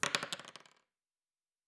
Dice Single 7.wav